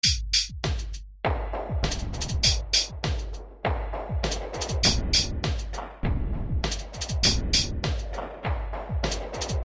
工业化霹雳拍子101
描述：来自噪音工厂的声音 :)
标签： 100 bpm Industrial Loops Drum Loops 1.62 MB wav Key : Unknown
声道立体声